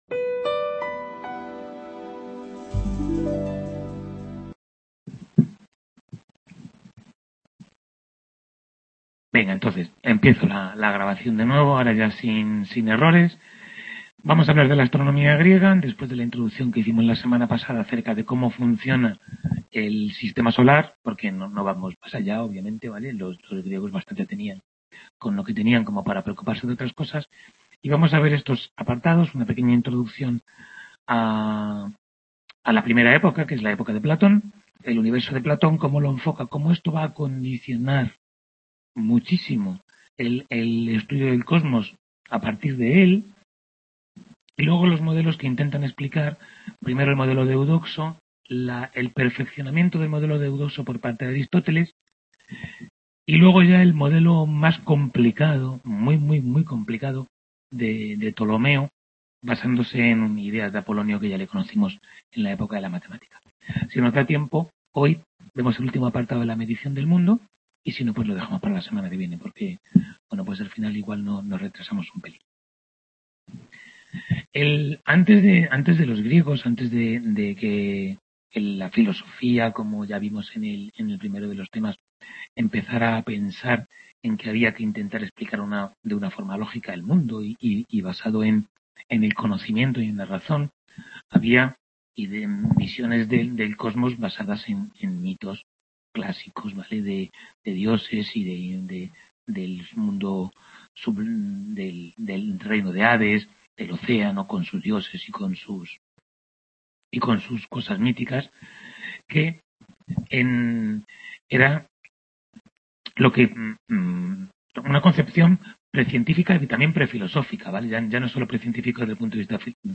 Tutoría 5 de Historia General de la Ciencia I | Repositorio Digital